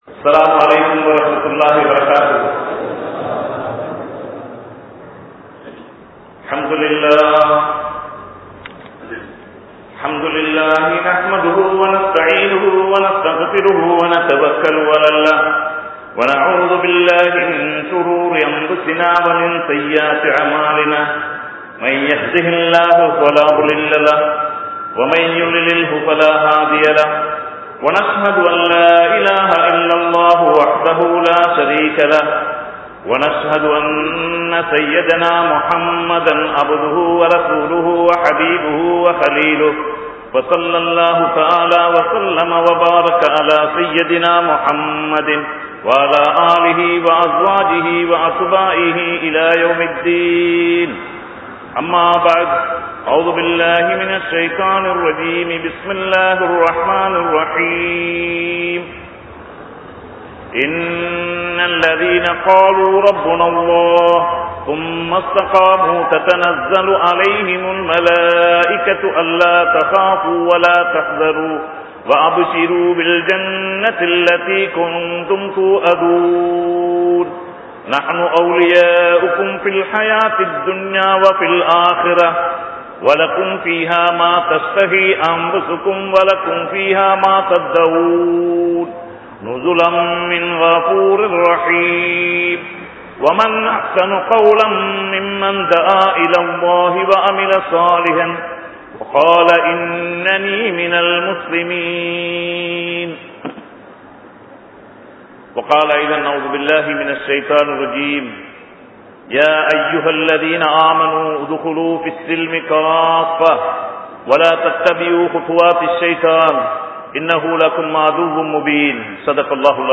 Galle, Dangadera, Badr Jumua Masjith